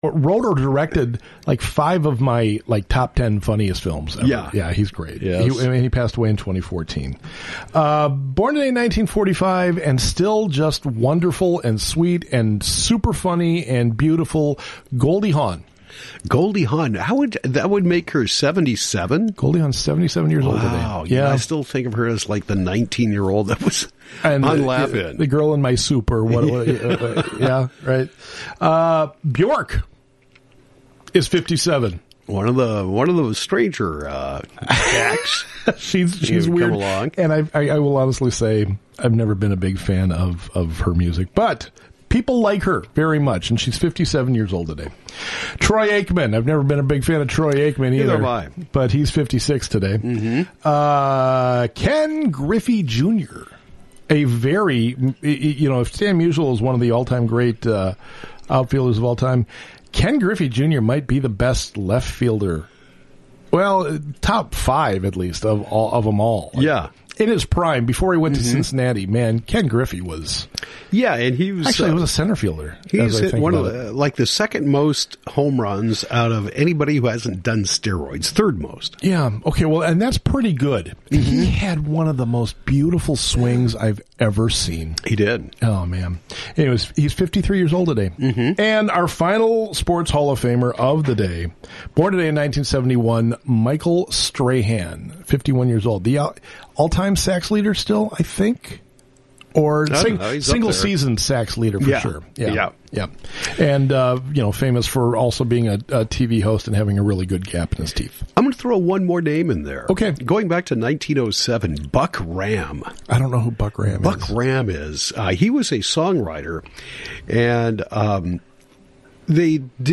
Legal Talk with Justice Gordon Moore - Post Election Conversation 11/21/22